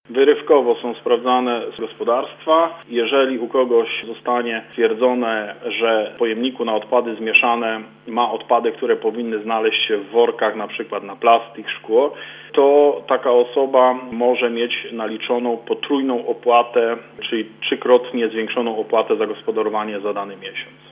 Mówi włodarz gminy, Sławomir Sidur.